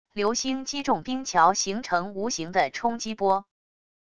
流星击中冰桥形成无形的冲击波wav音频